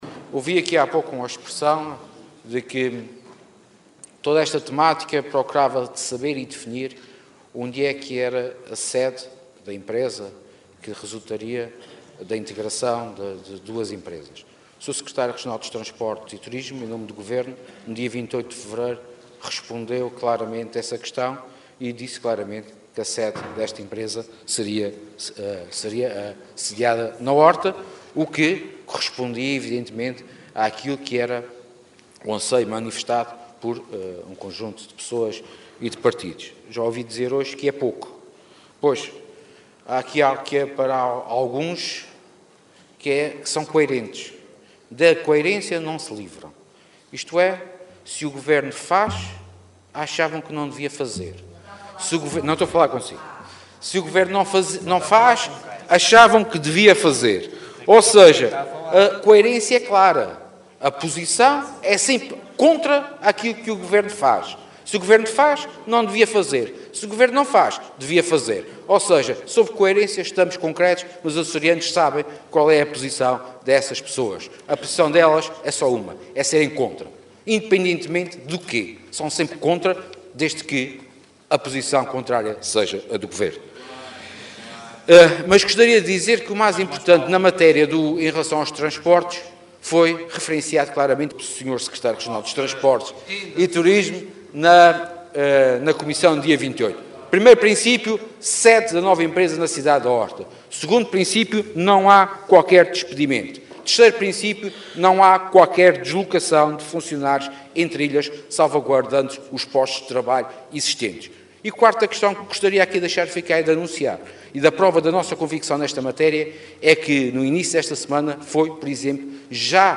Sérgio Ávila, que falava na Assembleia Legislativa, acrescentou que, no âmbito dessa fusão e da localização da sede, o Governo Regional também decidiu que “não há qualquer despedimento, não há qualquer deslocação de funcionários entre ilhas, salvaguardando os postos de trabalho existentes”.